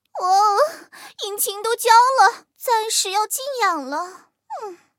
M4谢尔曼中破修理语音.OGG